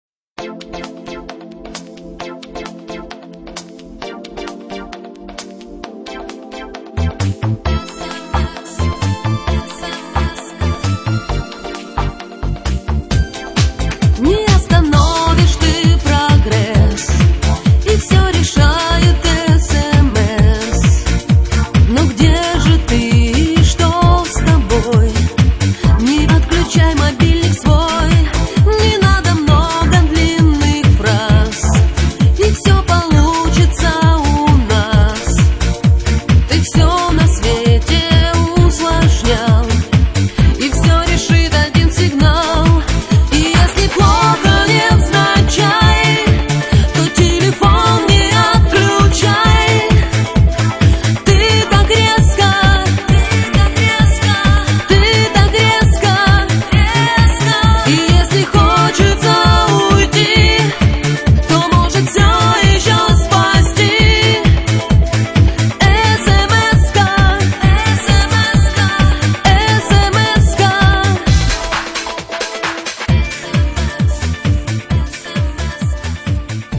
2004 Поп